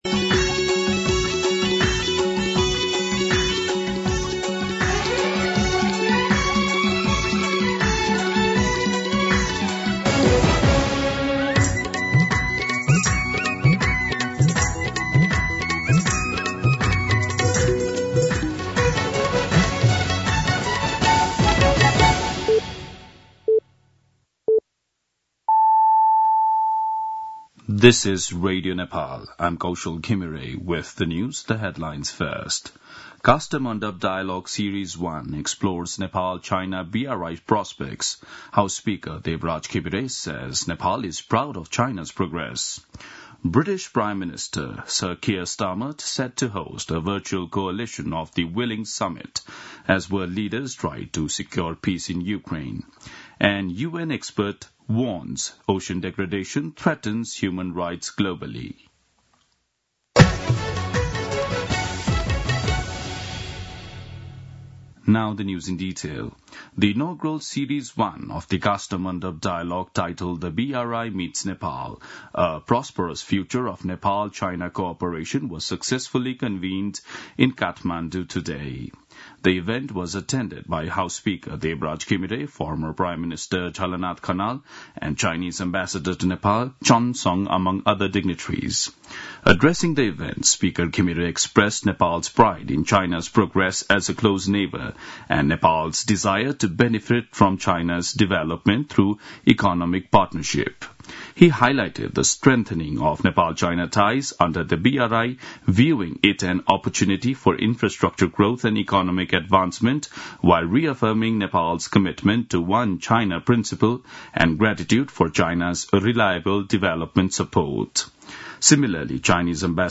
दिउँसो २ बजेको अङ्ग्रेजी समाचार : २ चैत , २०८१
2-pm-Engish-News-.mp3